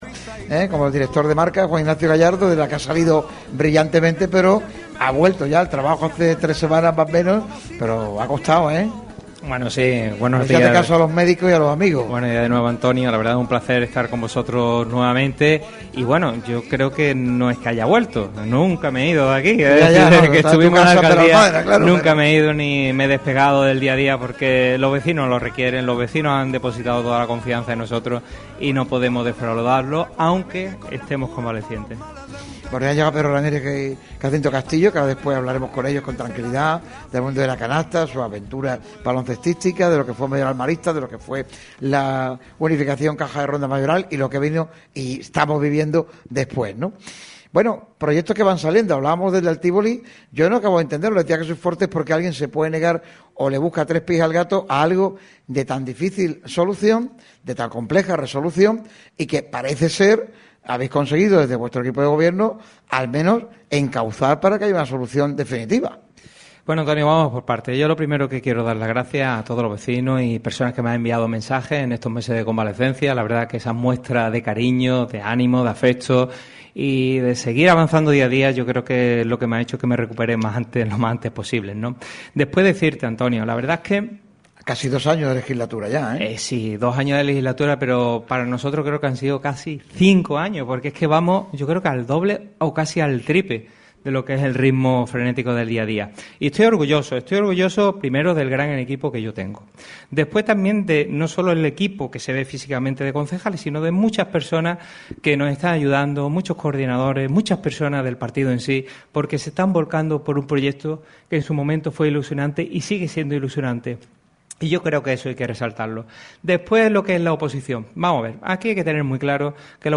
Lo ha hecho en un programa especial celebrado en la Casa de la Cultura de Benalmádena con el patrocinio de la Mancomunidad de Municipios de la Costa del Sol Occidental, ACOSOL y el propio Ayuntamiento de Benalmádena.